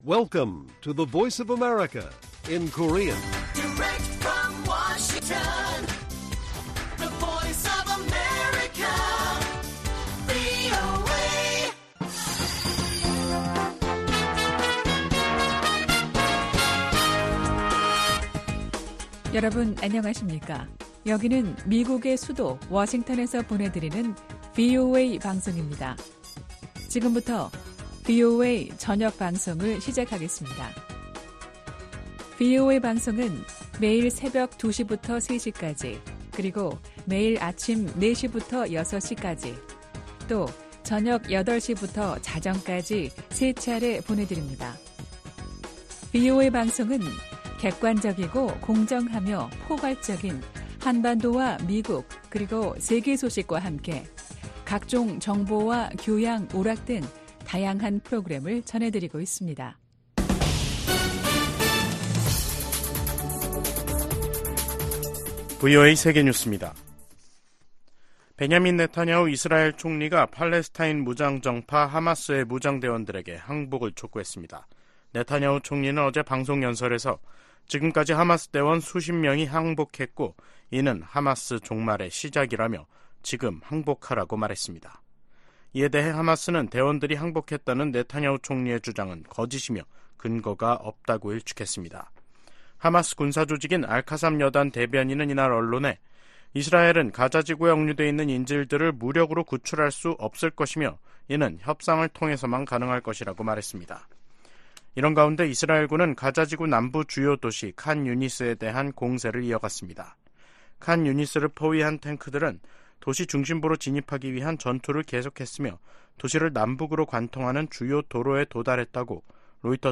VOA 한국어 간판 뉴스 프로그램 '뉴스 투데이', 2023년 12월 11일 1부 방송입니다. 미국과 한국, 일본이 새 대북 이니셔티브를 출범하면서 북한 정권의 핵과 미사일 기술 고도화의 자금줄 차단 등 공조를 한층 강화하겠다고 밝혔습니다. 미 상하원의원들이 중국 시진핑 정부의 탈북민 강제 북송을 비판하며, 중국의 인권이사국 자격 정지 등 유엔이 강력한 대응을 촉구했습니다. 영국 의회가 북한의 불법 무기 개발과 인권 문제 등을 다룰 예정입니다.